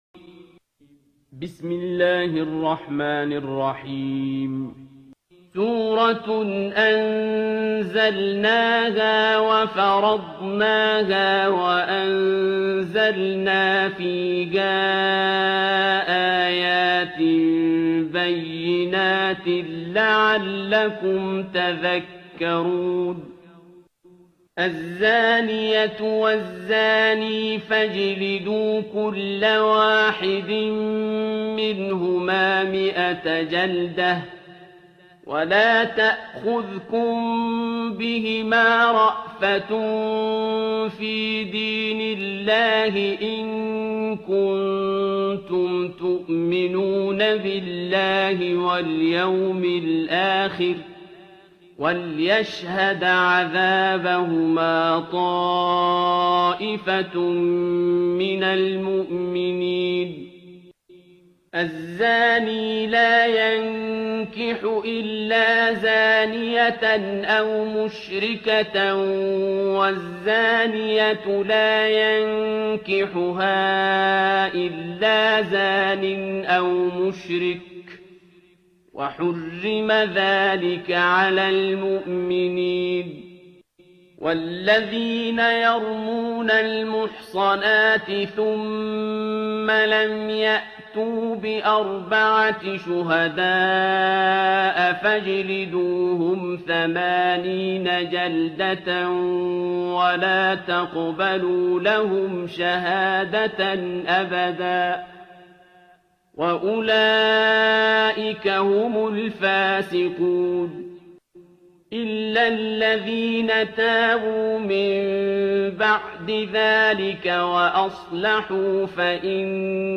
ترتیل سوره نور با صدای عبدالباسط عبدالصمد
024-Abdul-Basit-Surah-Al-Noor.mp3